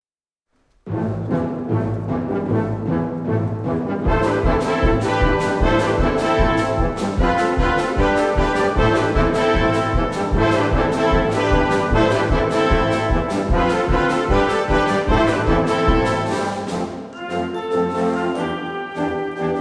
Catégorie Harmonie/Fanfare/Brass-band
Sous-catégorie Musique de concert